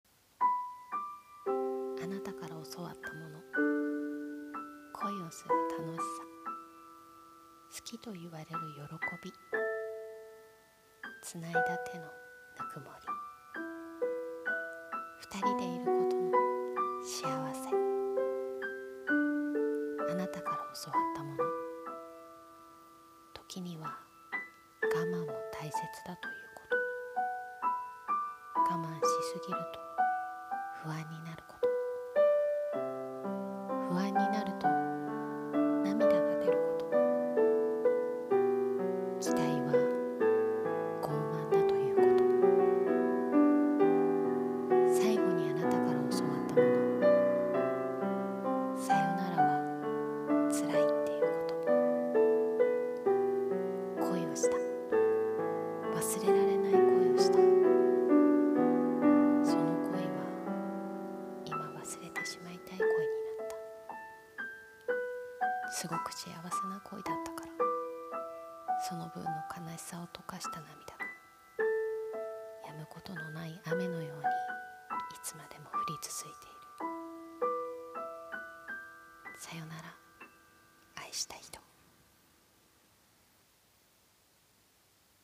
声劇【サヨナラ愛した人】